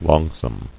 [long·some]